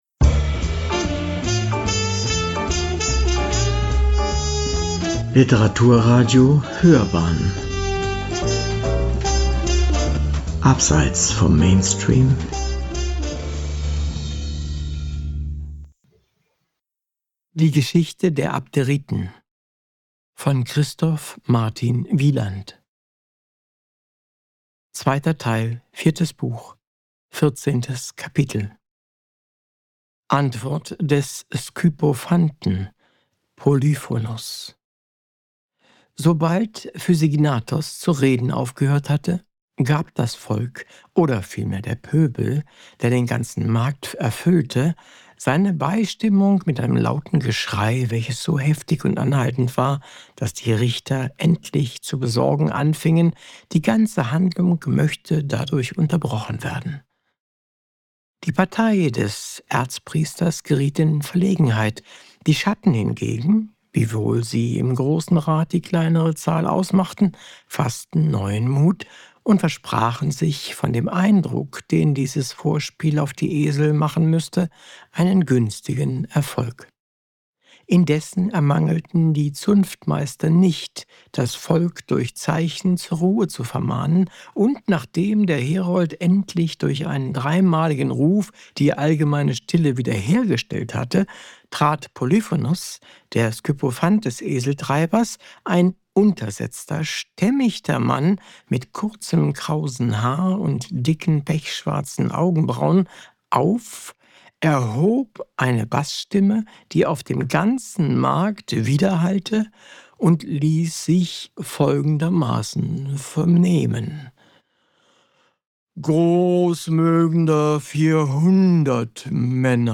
Gemeinsam mit vielen unserer talentierten Sprecherinnen und Sprecher haben wir das Buch “Geschichte der Abderiten” von Christoph Martin Wieland vertont.